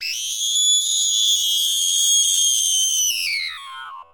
Shrilly goat scream sound effect
Loud Scary Scream Yell sound effect free sound royalty free Voices